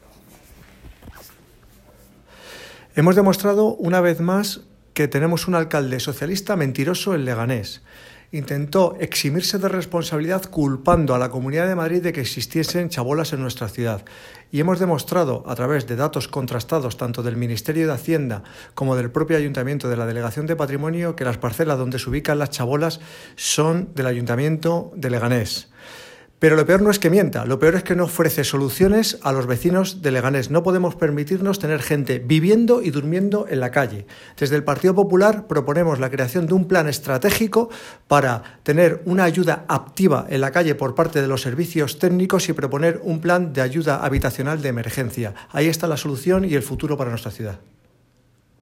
Declaraciones Miguel Ángel Recuenco: